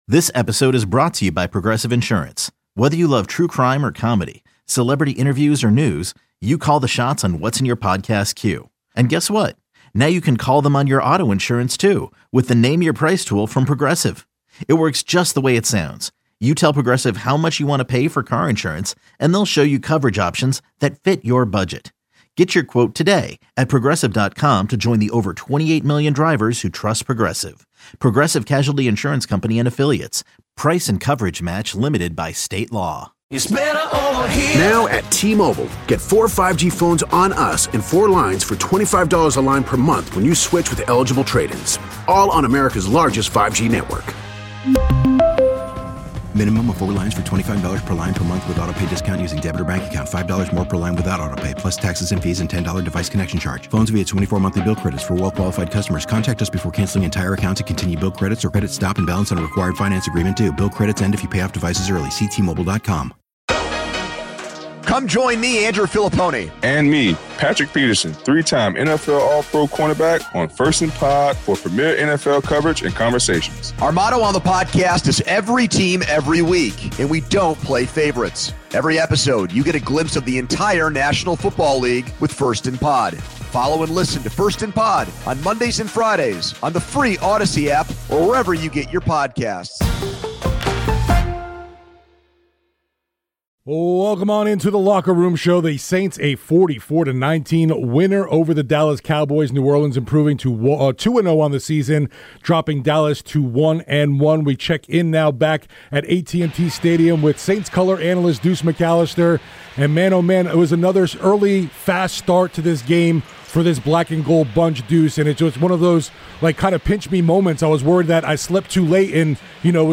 Saints legend Deuce McAllister broke down New Orleans' dominant 44-19 victory over the Dallas Cowboys. Deuce praised the Saints' offense, highlighting the stellar play of the offensive line and RB Alvin Kamara.